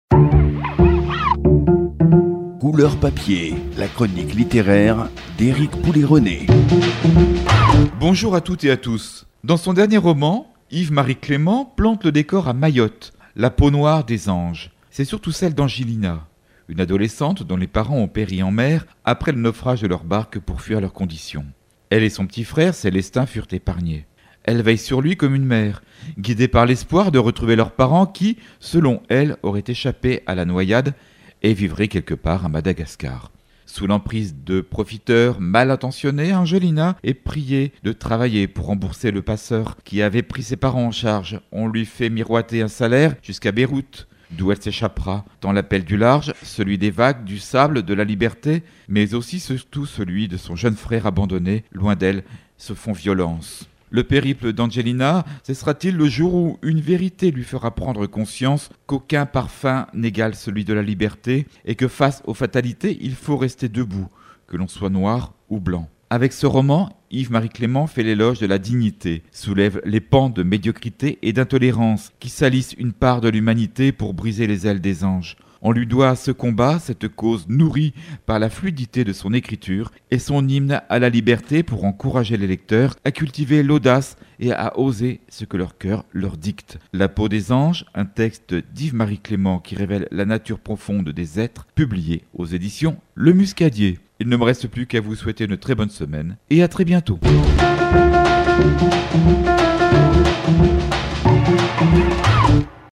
diffusée sur la radio Triage FM